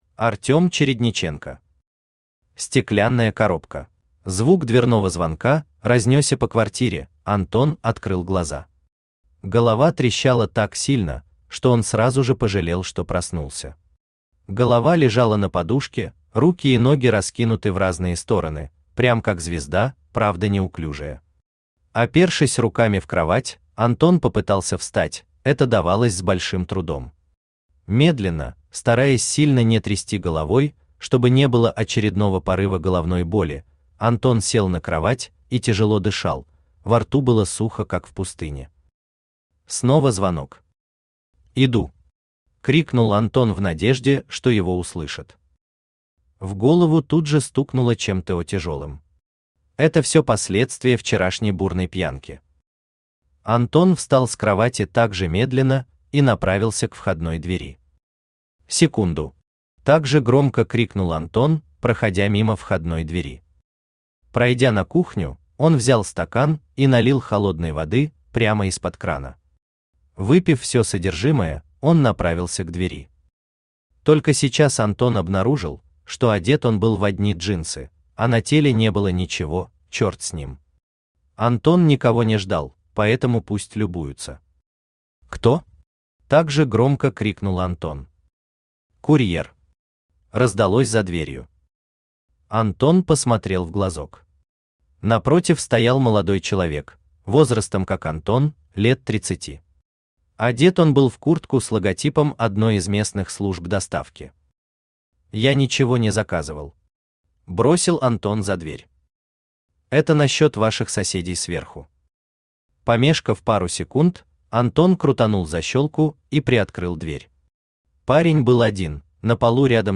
Аудиокнига Стеклянная коробка | Библиотека аудиокниг
Aудиокнига Стеклянная коробка Автор Артём Андреевич Чередниченко Читает аудиокнигу Авточтец ЛитРес.